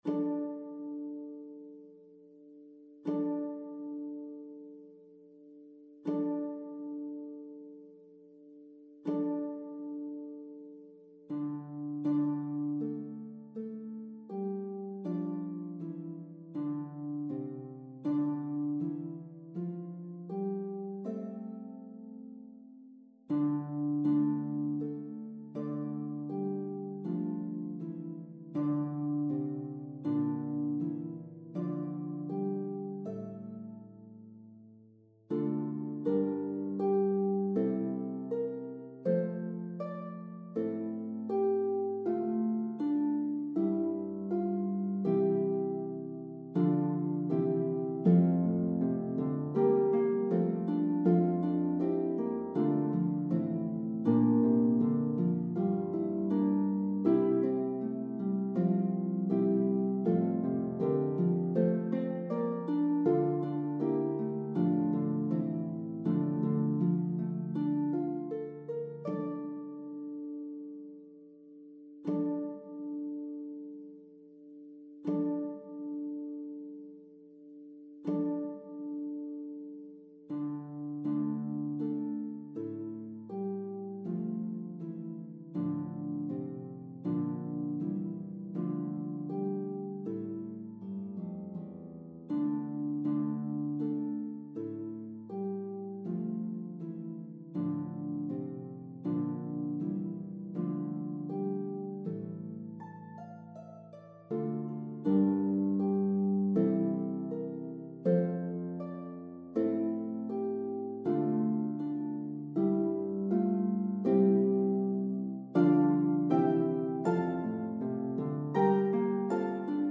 A beloved Christmas Carol from the Renaissance period.